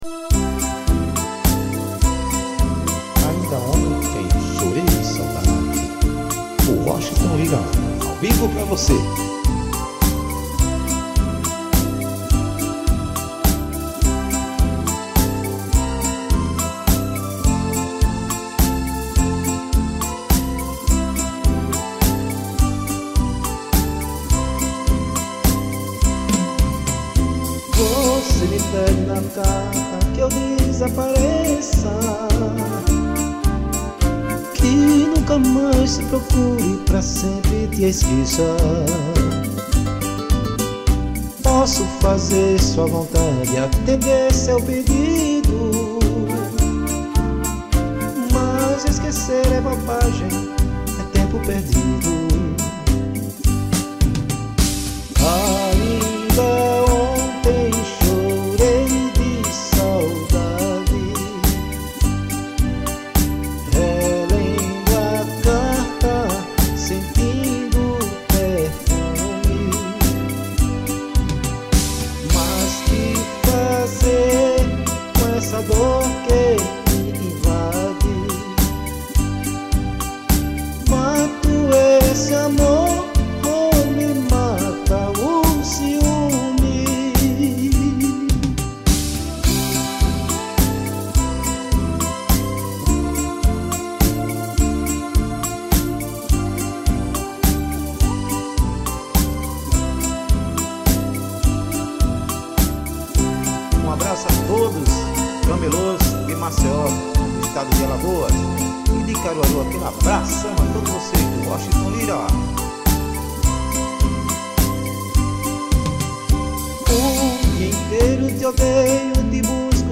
Ao vivo teclado.